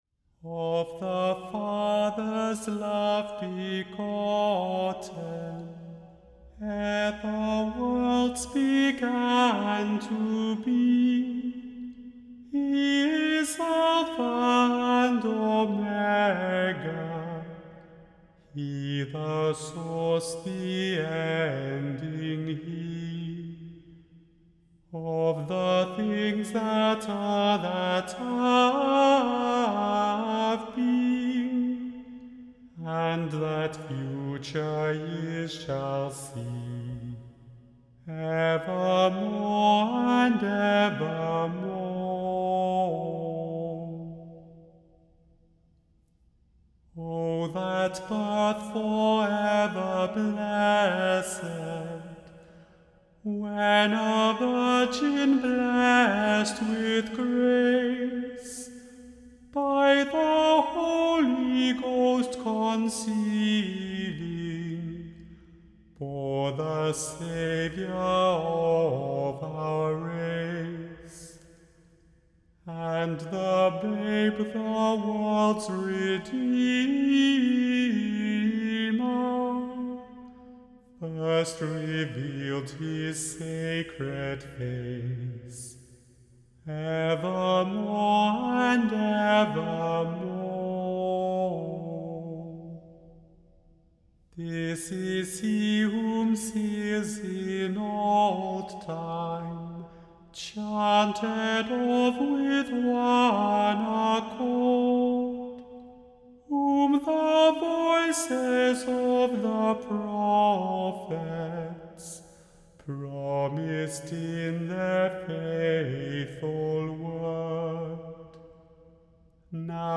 The Chant Project – Chant for Today (July 5) – Of the father’s love begotten